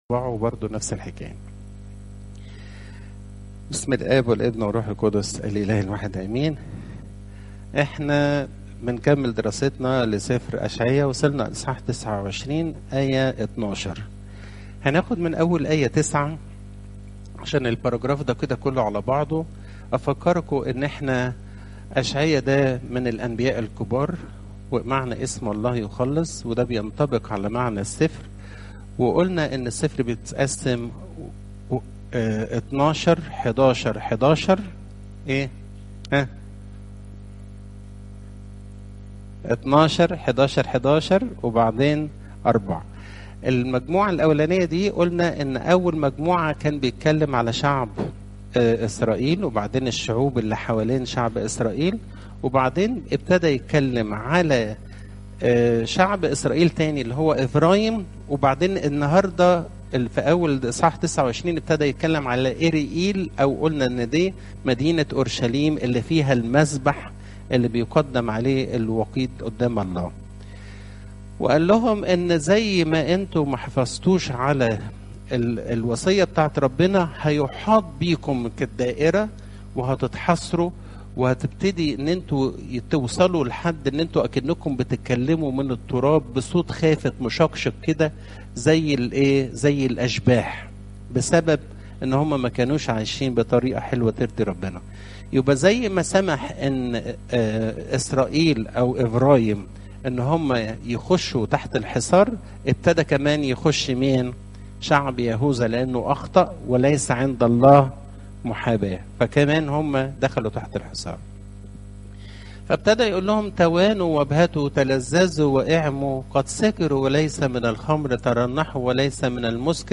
عظات الكنيسة - بطريركية الاقباط الارثوذكس - كنيسة السيدة العذراء مريم بالزيتون - الموقع الرسمي